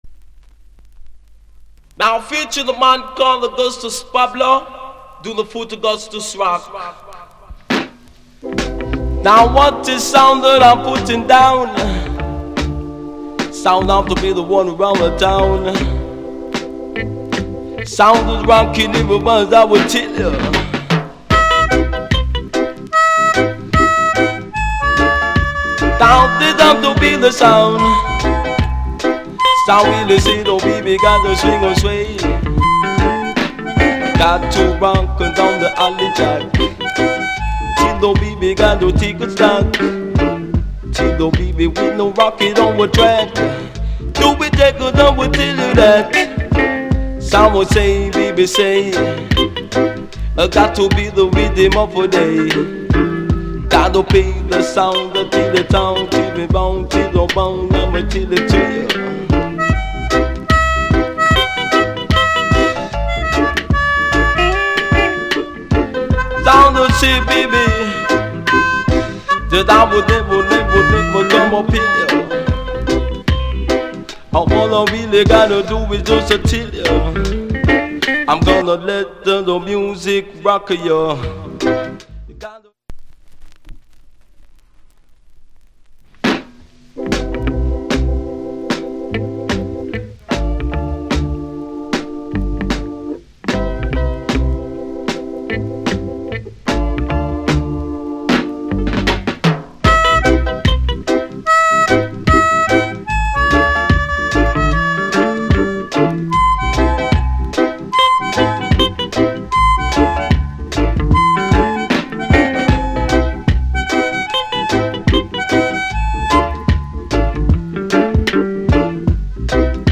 Genre Roots Rock Reggae70sMid / [A] Male DJ [B] Inst